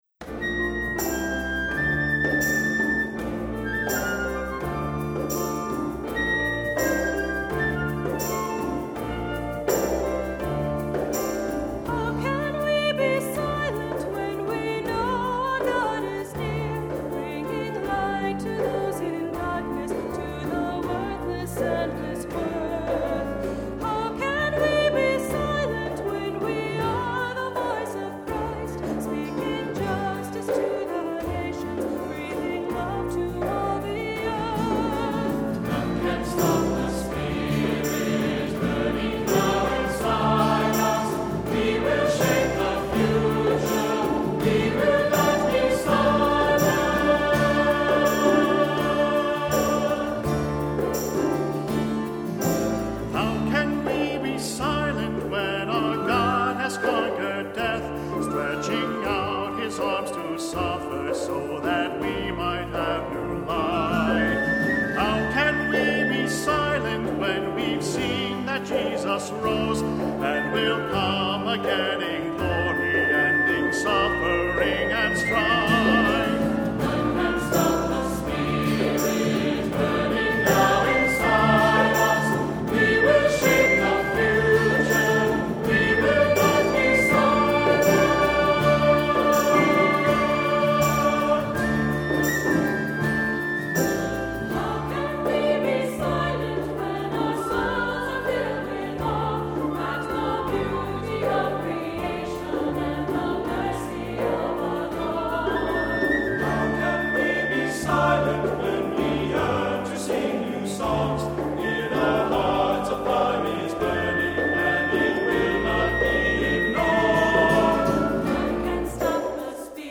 Accompaniment:      Keyboard, C Instrument
Music Category:      Christian